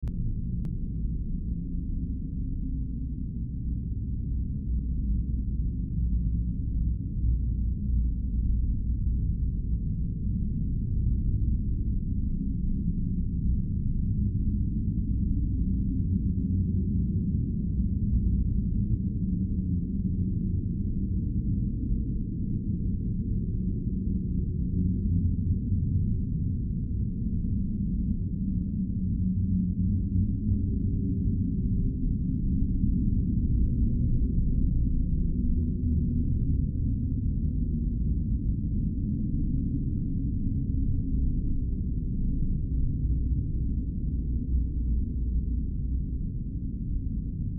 دانلود آهنگ باد 49 از افکت صوتی طبیعت و محیط
دانلود صدای باد 49 از ساعد نیوز با لینک مستقیم و کیفیت بالا
جلوه های صوتی